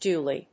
ドゥーリー